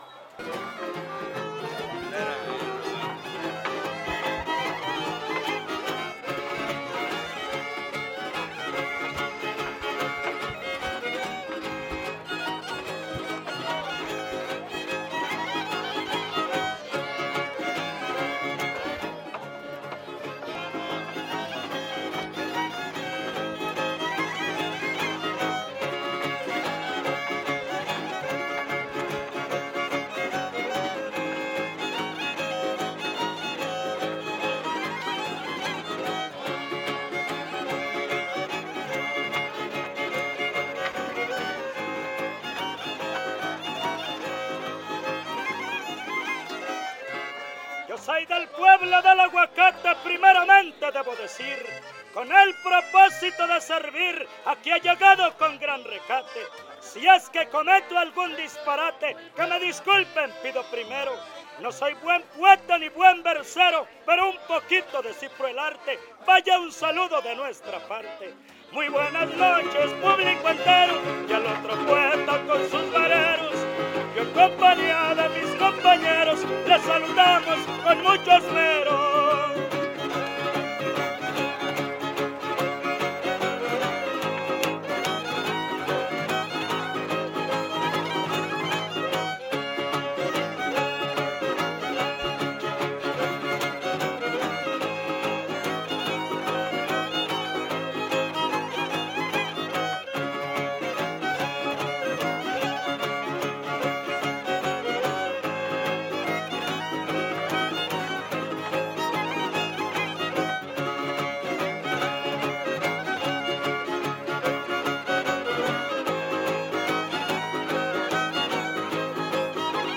Huapango arribeño
Guitarra Violín Vihuela
Topada ejidal: Cárdenas, San Luis Potosí